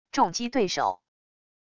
重击对手wav音频